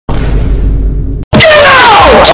Vine Boom GET OUT Soundboard: Play Instant Sound Effect Button
This high-quality sound effect is part of our extensive collection of free, unblocked sound buttons that work on all devices - from smartphones to desktop computers.